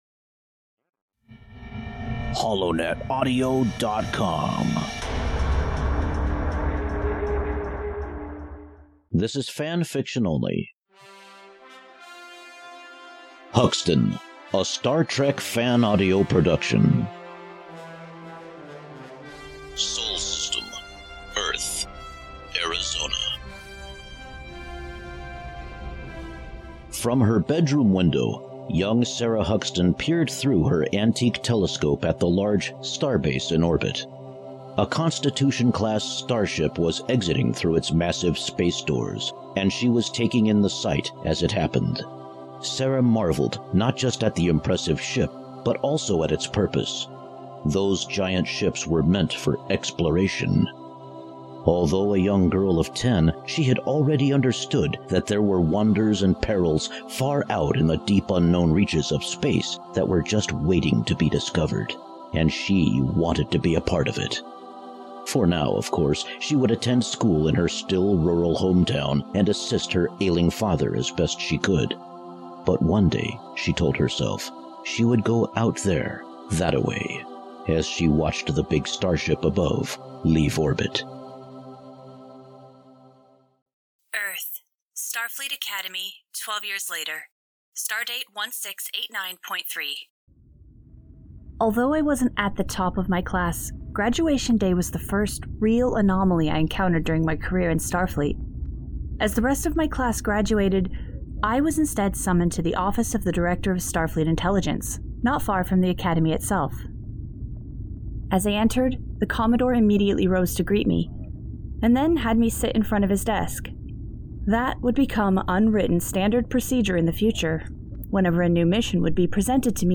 Audio Books/Drama Author(s
HUXTON-a-STAR-TREK-fan-audio-production.mp3